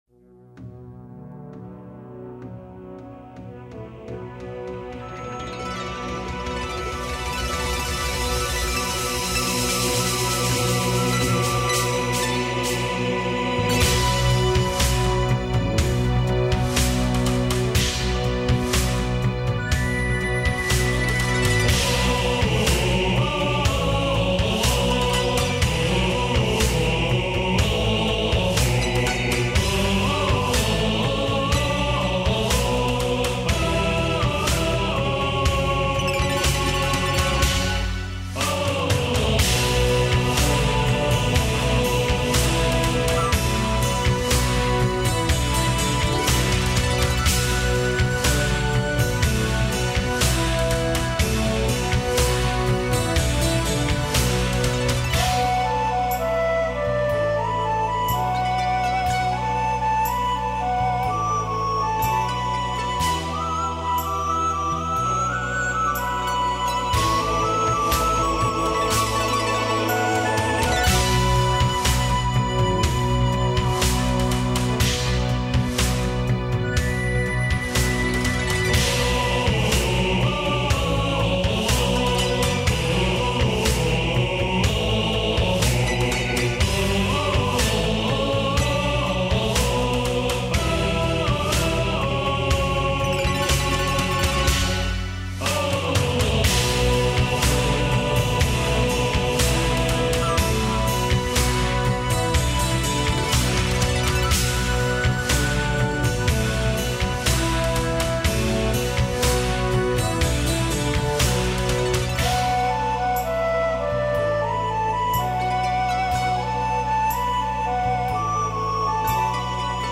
其實這些曲目...是早期霹靂跟天宇所用的人物場景配樂，不過事實上...這些是用很多動畫或是一些其他音樂集改編而來的，